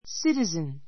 citizen A2 sítəzn スィ ティ ズ ン 名詞 ❶ 市民, （都会の）住民 a citizen of New York City a citizen of New York City ニューヨーク市民 a citizen of Tokyo a citizen of Tokyo 東京都民 ❷ 国民 an American citizen an American citizen アメリカ国民